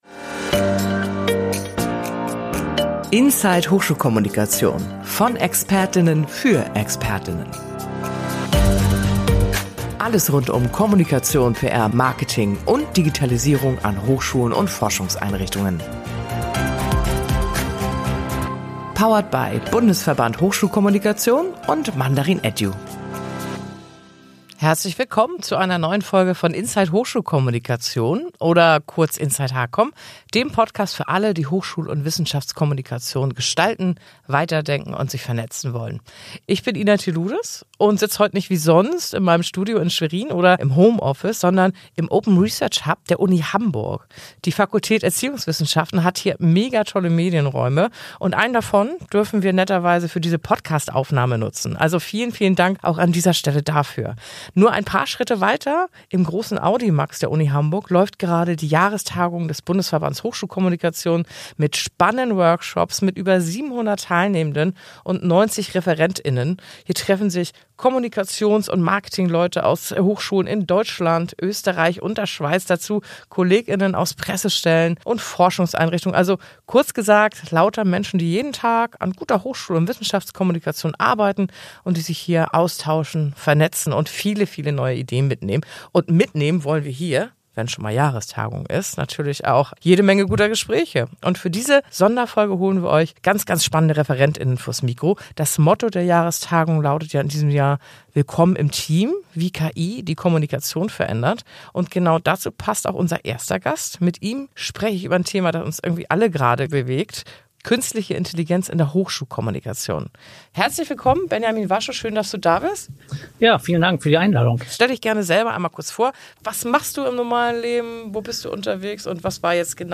Drei Gäste, drei Perspektiven, ein Thema: Wie verändern sich Strukturen, Tools und Haltungen in der Hochschulkommunikation?
Eine Folge direkt vom Branchentreffen der Hochschulkommunikation – voller Praxisbeispiele, mutiger Thesen und Inspiration für alle, die Hochschul- und Wissenschaftskommunikation gestalten.